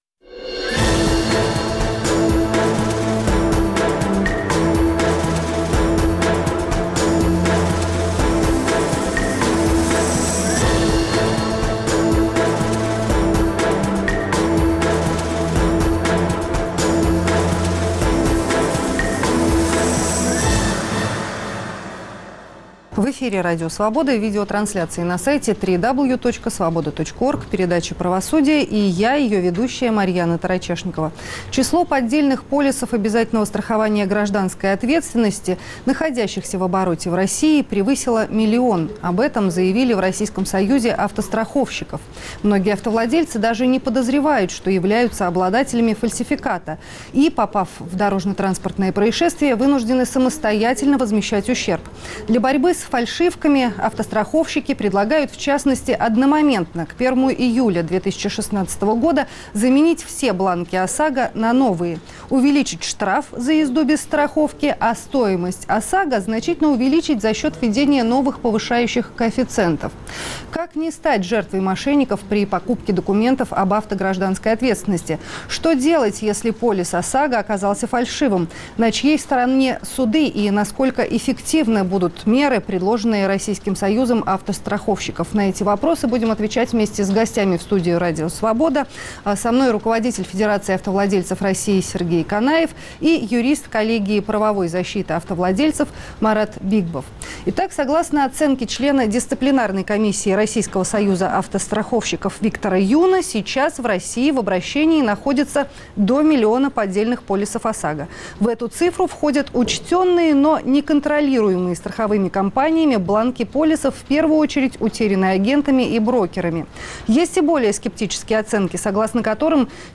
На эти вопросы мы будем отвечать вместе с гостями.